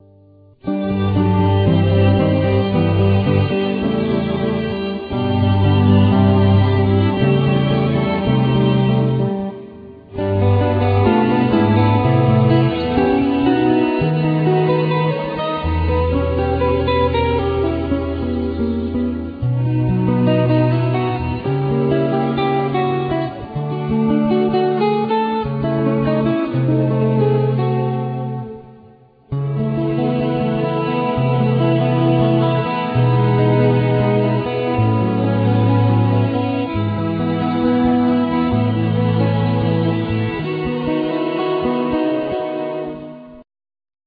Piano
Guitar,Base,Sequencers